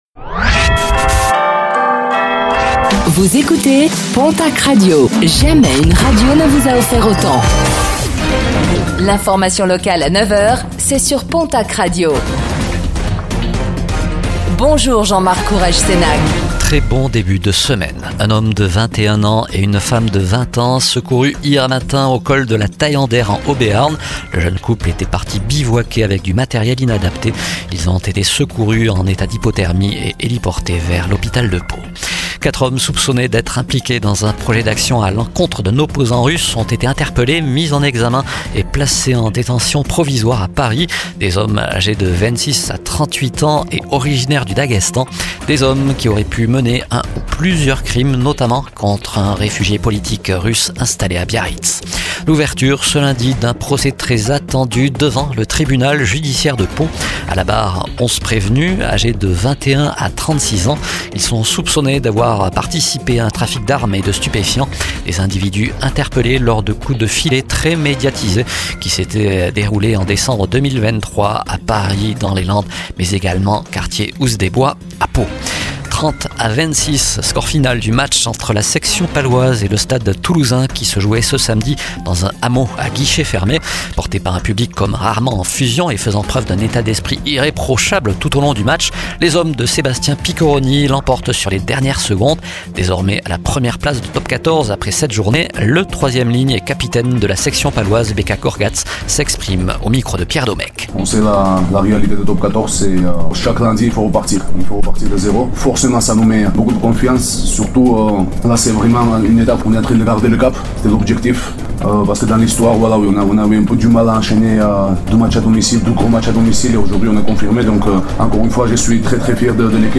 Réécoutez le flash d'information locale de ce lundi 20 octobre 2025